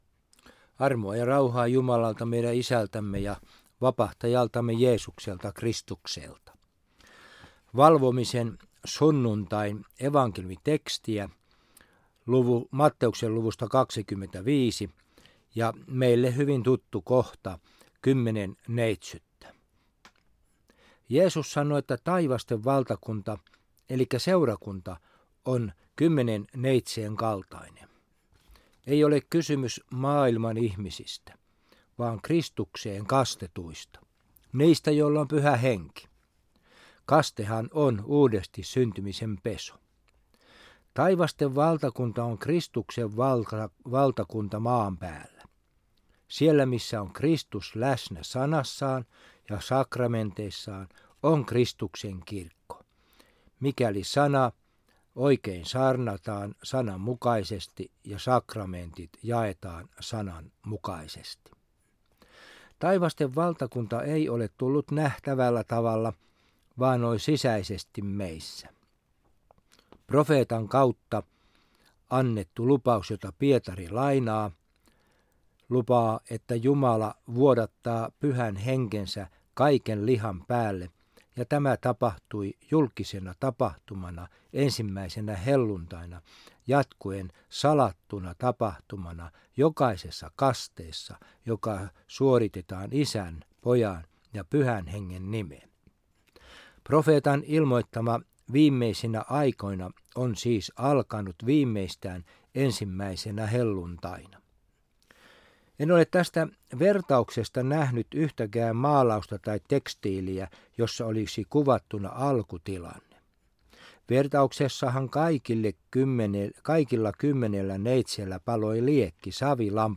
Evankeliumisaarna Järviradioon